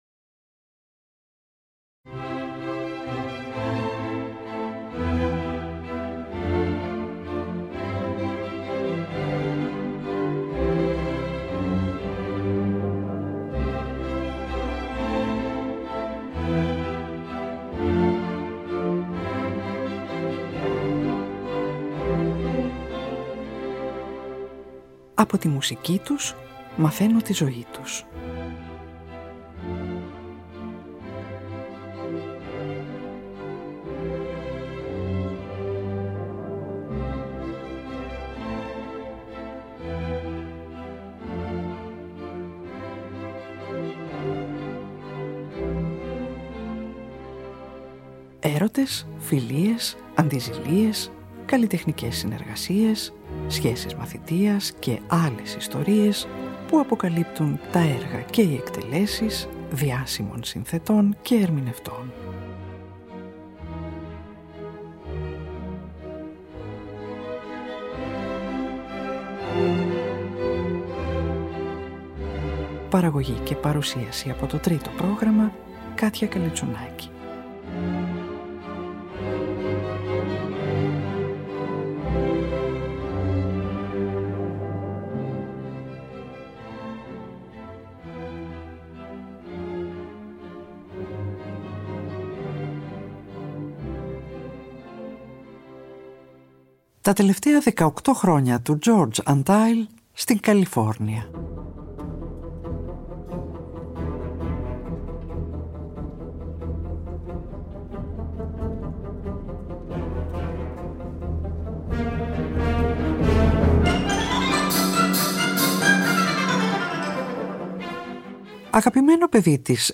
για ορχήστρα εγχόρδων